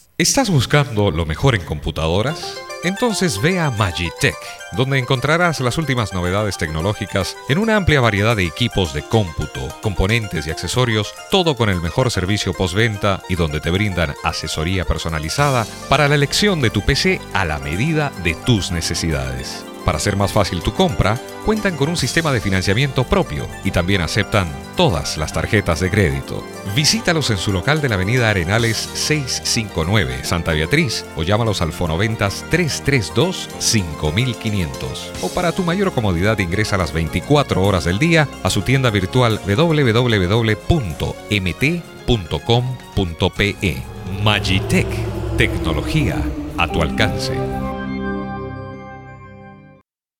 spanisch Südamerika
Sprechprobe: Industrie (Muttersprache):
spanish male voice over artist